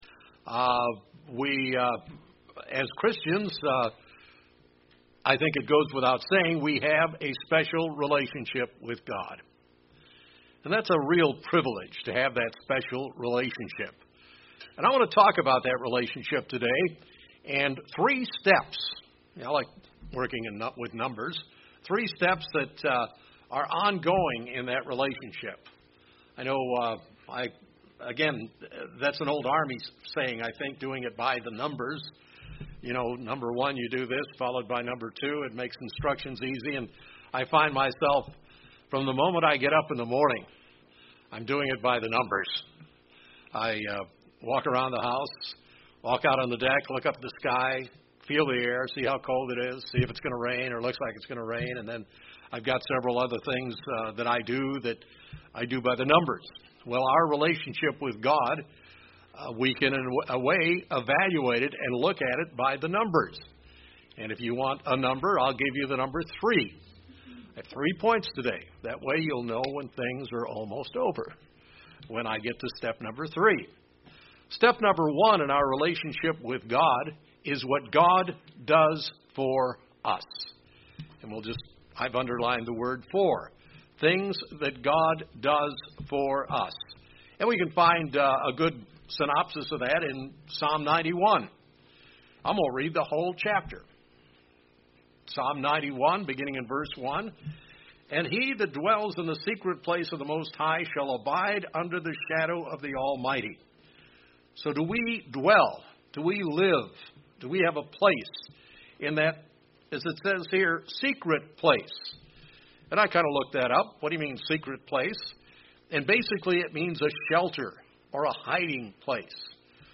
A sermon looking into our relationship with God as Christians and how we can establish and maintan our relationship with Him.